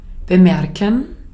Ääntäminen
IPA : /ˈnəʊ.tɪs/
IPA : /ˈnoʊtɪs/